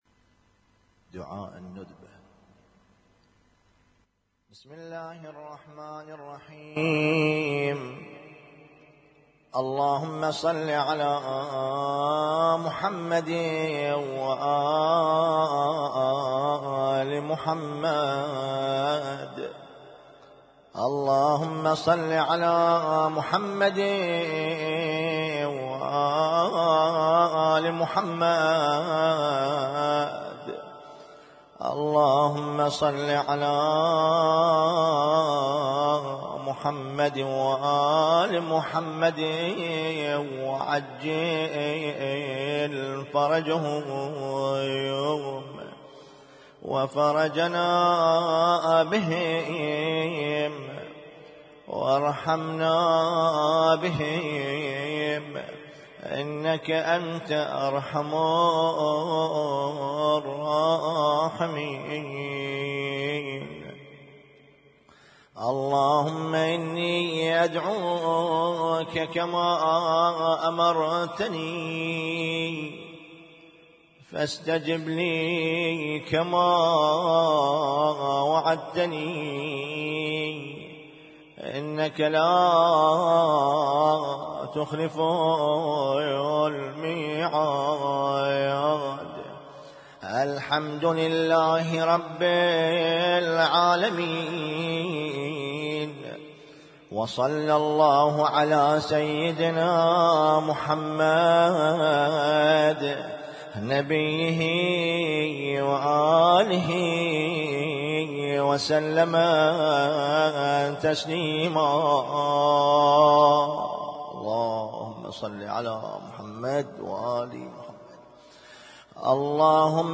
Husainyt Alnoor Rumaithiya Kuwait
اسم التصنيف: المـكتبة الصــوتيه >> الادعية >> الادعية المتنوعة